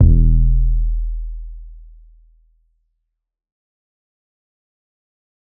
TC 808 3.wav